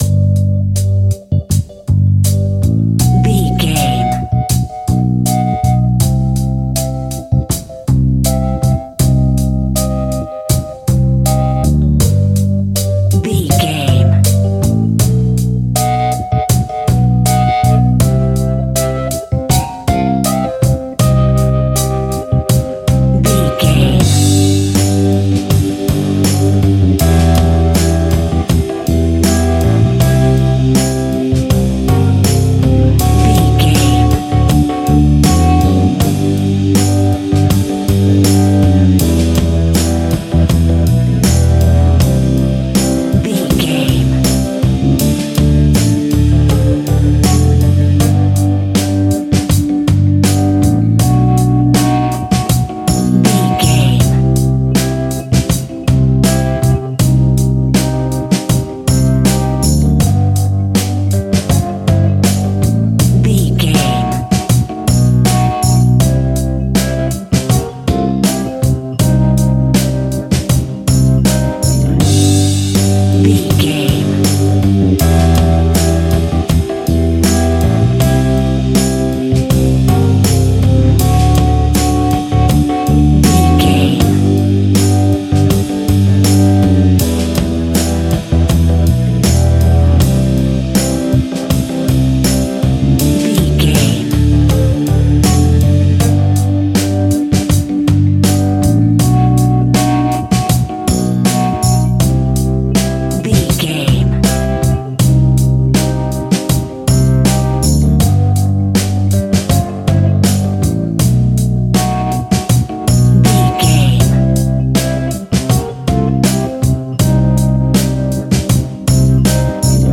Ionian/Major
funky
uplifting
bass guitar
electric guitar
organ
drums
saxophone
groovy